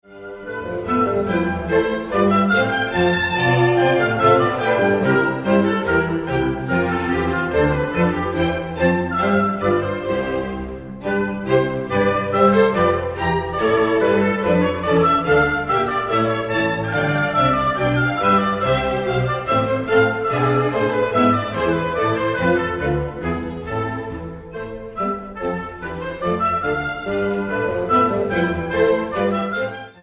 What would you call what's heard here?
Genre: Classical